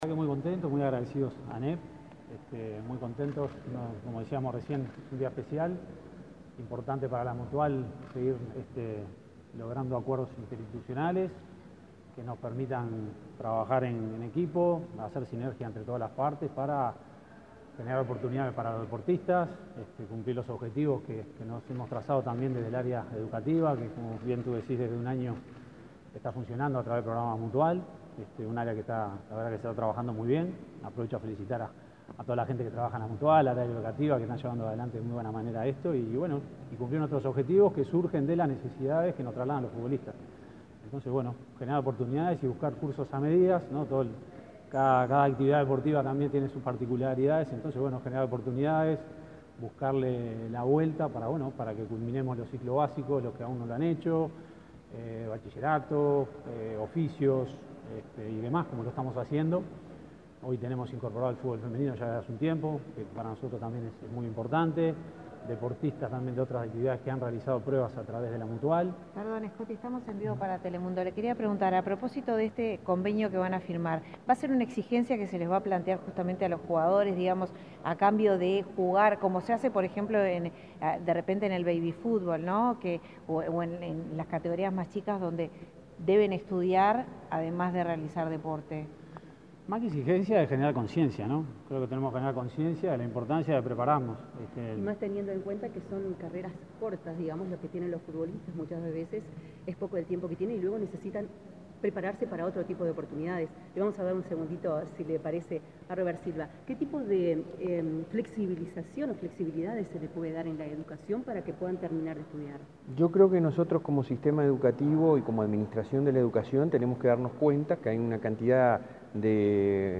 Declaraciones de prensa de Diego Scotti y Robert Silva
Luego el presidente de la ANEP, Robert Silva, y el titular de la mutual, Diego Scotti, dialogaron con la prensa.